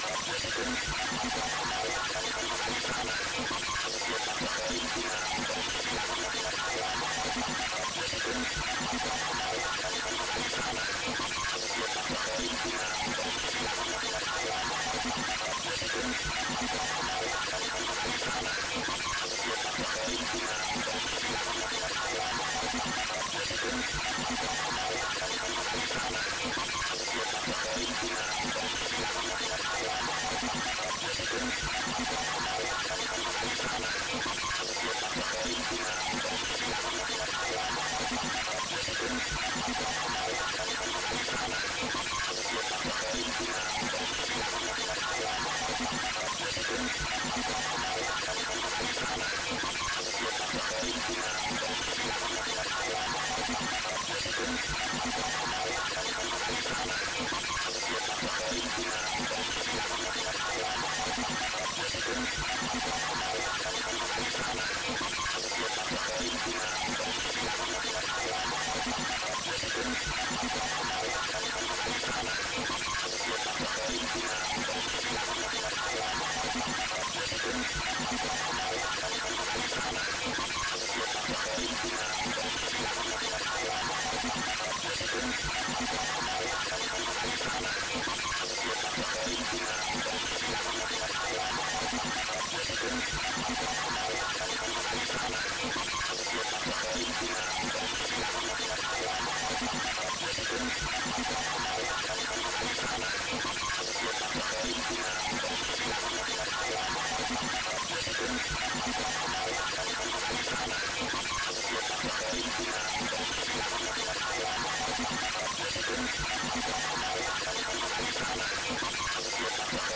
外星人Speek高频率192khz
描述：一个声音，我想id分享。高频兴奋的外星人西伯利亚。 。 。
Tag: 激励 复杂的声音 高保真频率